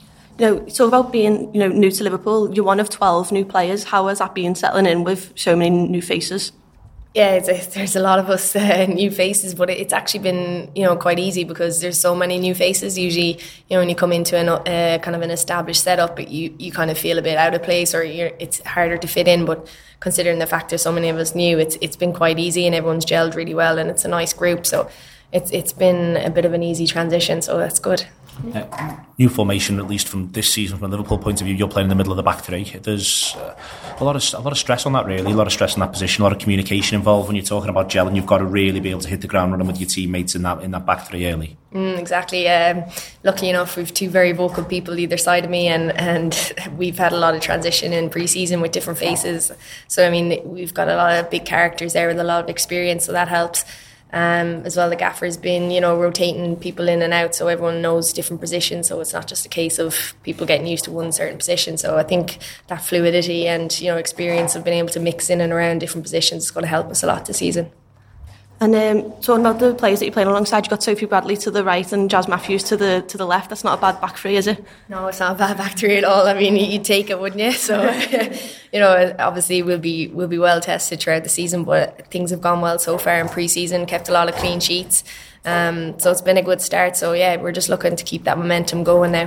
In conversation: Niamh Fahey